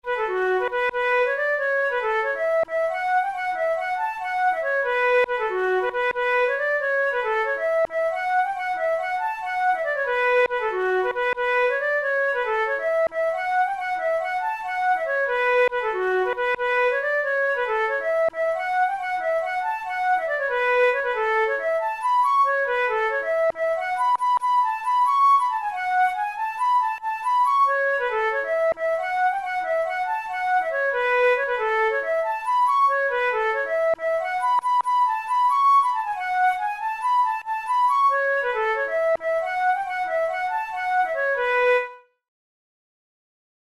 Traditional Scottish march